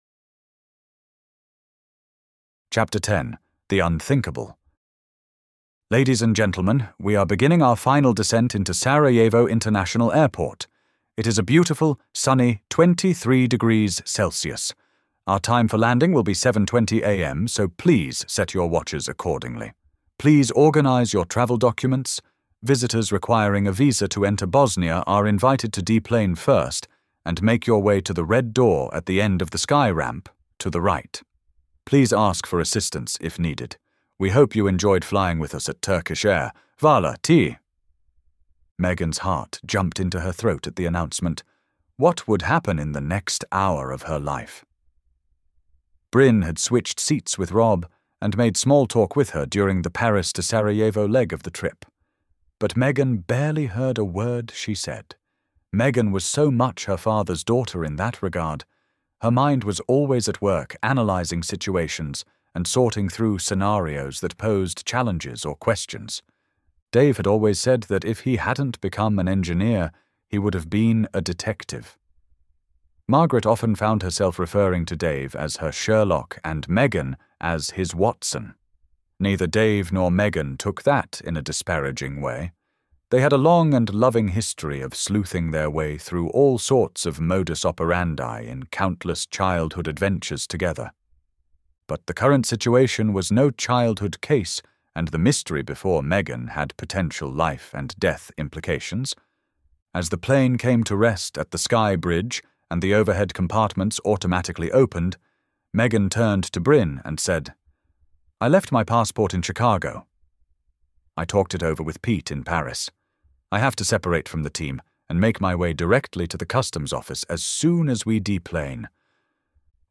Listen to the entirety of Chapter 10: "The Unthinkable" in the audiobook version of my sci-fi-turned-spiritual thriller: 'DACLAXVIA', HERE (click to listen).